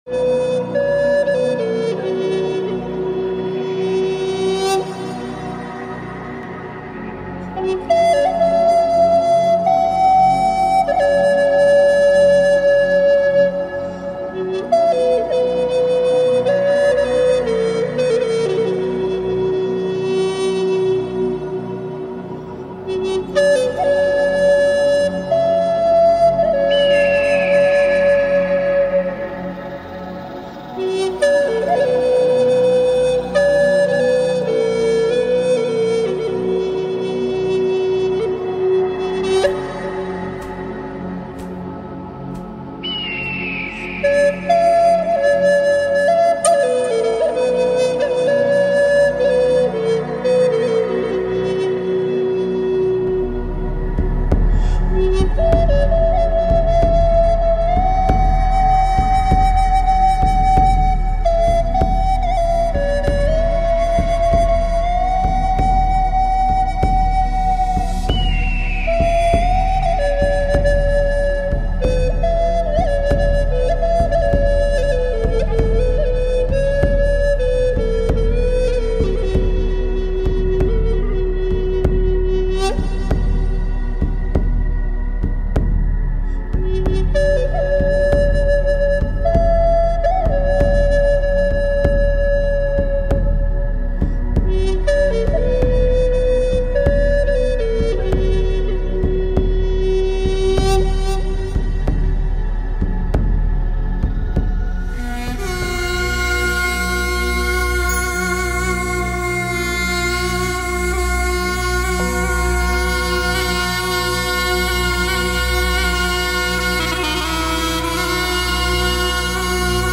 Sound Journey